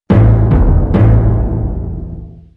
定音鼓.wav
乐器类/重大事件短旋律－宏大/定音鼓.wav
• 声道 立體聲 (2ch)